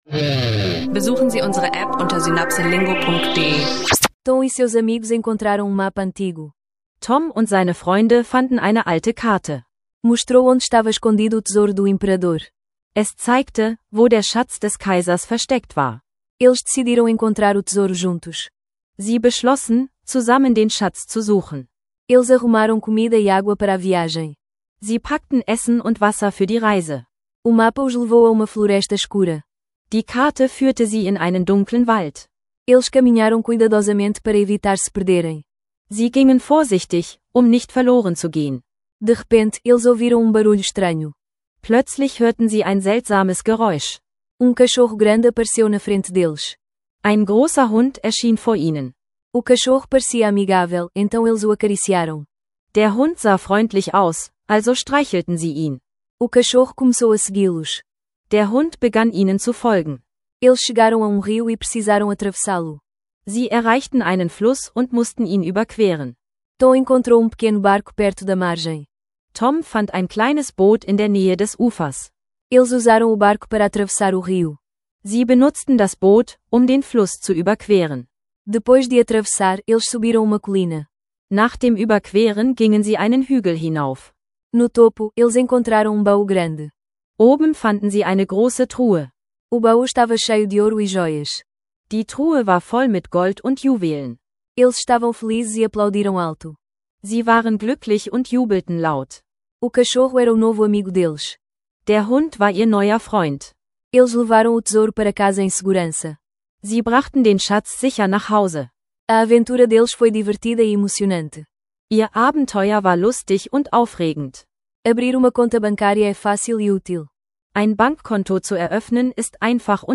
In dieser Folge erfahren Sie, wie Sie Portugiesisch lernen mit Praxisbeispielen aus Alltag, Abenteuern und Kultur. Ideal für Anfänger, die Portugiesisch lernen kostenlos und mit Spaß möchten – inklusive interaktiver Audiolektionen für unterwegs.